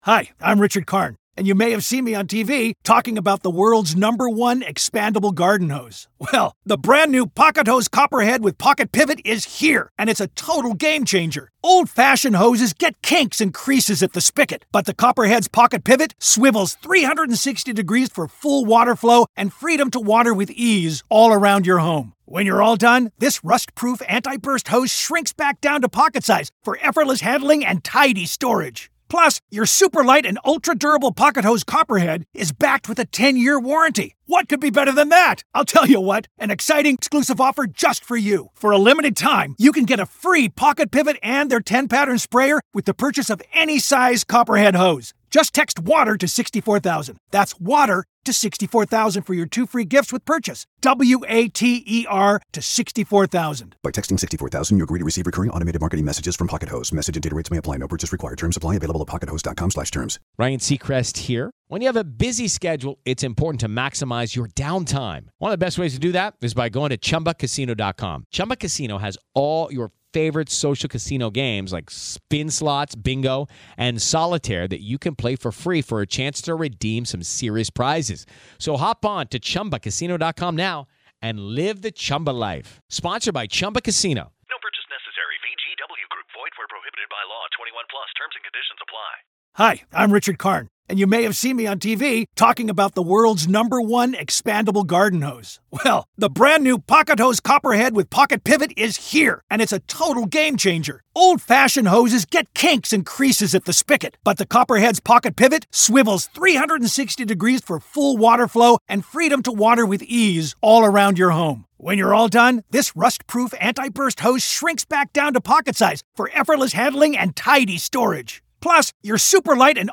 Special Prosecutor Asserts Alec Baldwin's Recklessness on 'Rust' Set In Opening Statement